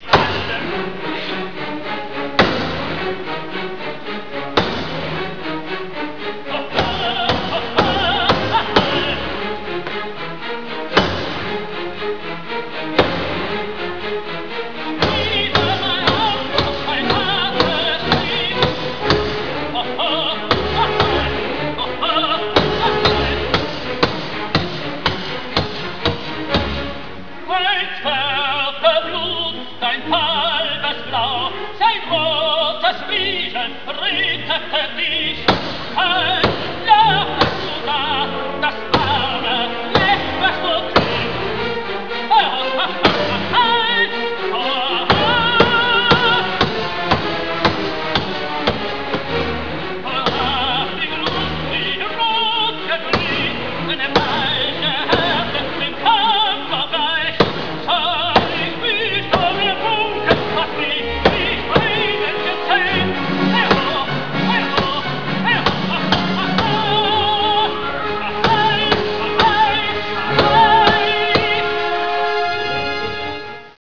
Es wird heiß gemacht, er klopft unter Gesang mit einem Hammer darauf herum ("